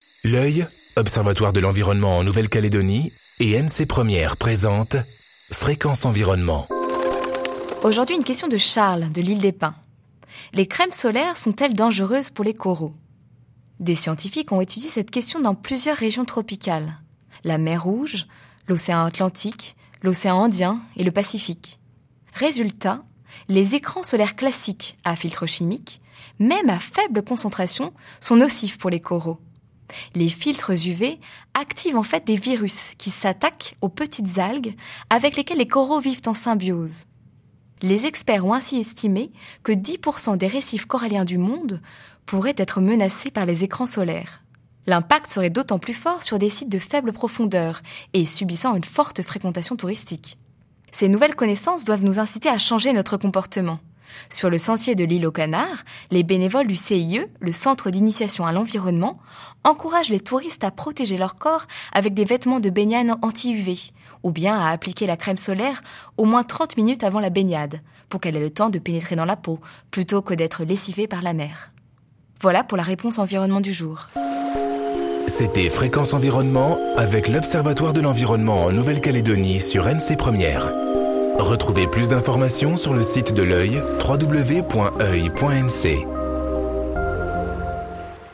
diffusée en janvier 2014 sur NC 1ère